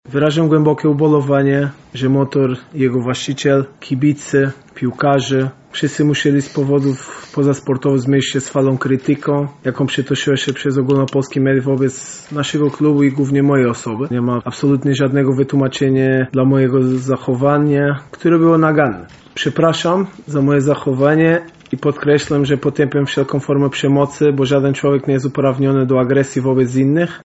Trener Goncalo Feio także wygłosił dziś swoje oświadczenie.